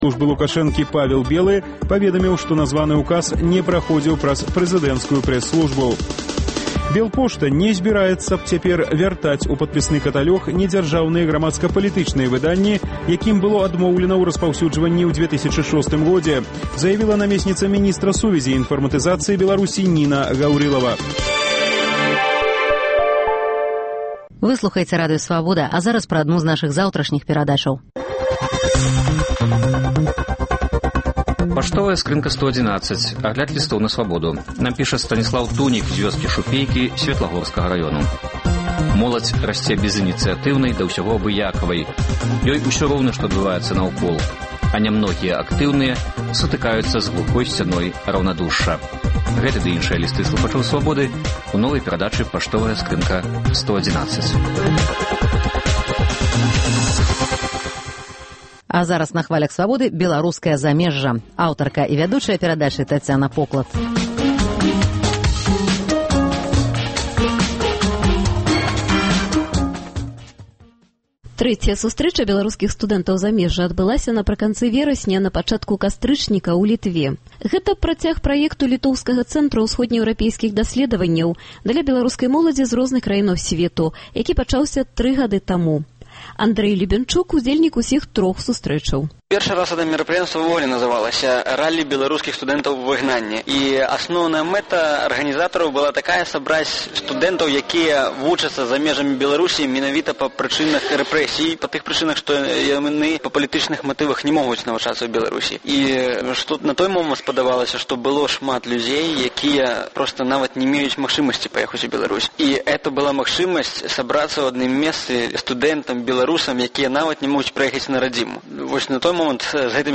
Для чаго студэнты зьбіраюцца на гэтыя сустрэчы і чым адметны сёлетні з'езд - у новай перадачы Беларускае замежжа распавядаюць студэнты з Эўропы і Амэрыкі.